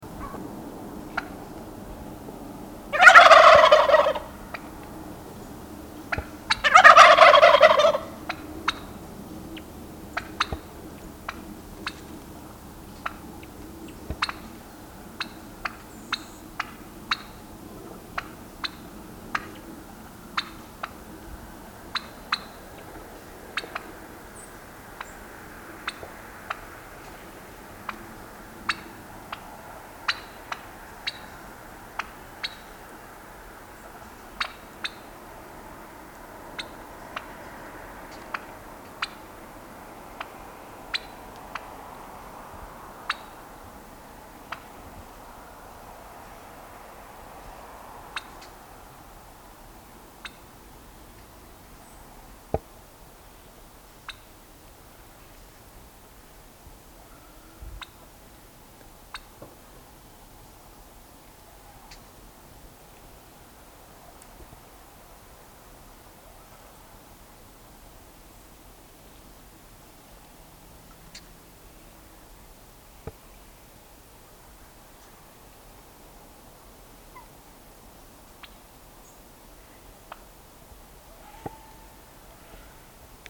Звук индюка самца